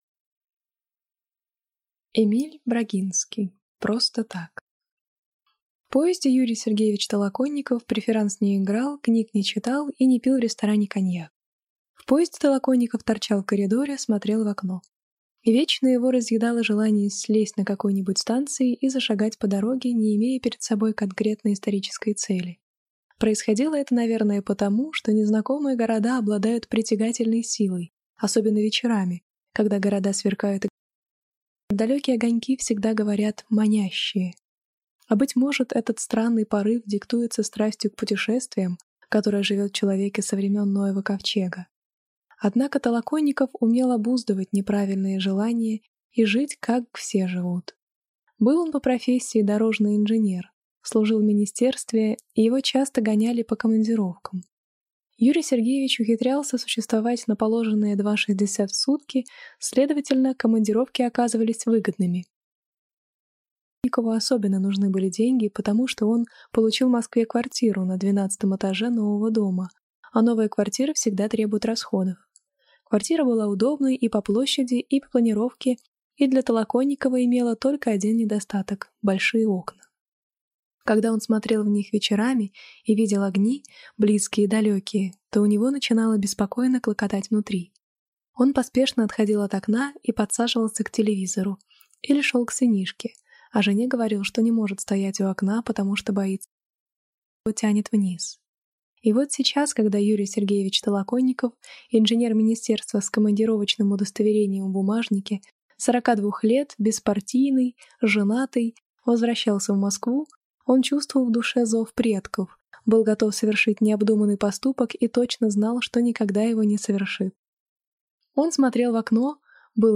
Аудиокнига Просто так | Библиотека аудиокниг